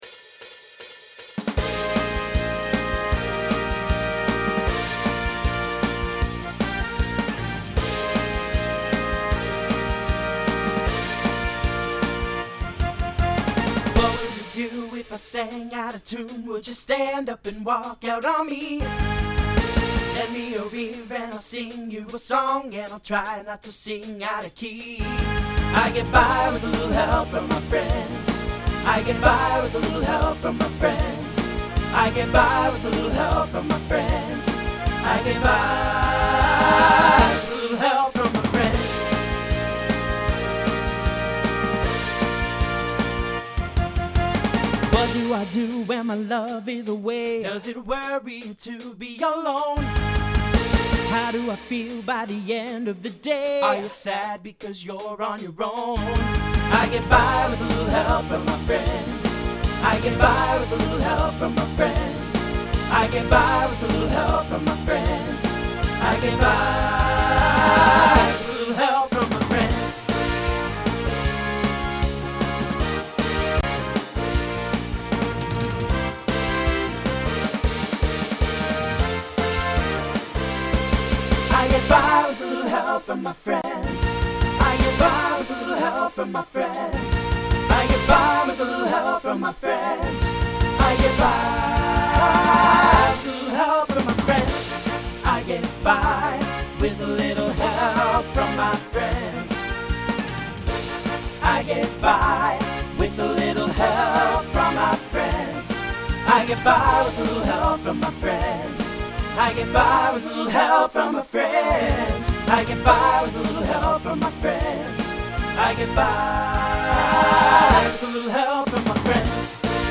New SSA voicing for 2025.